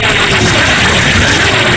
artillery_projectile_1.mp3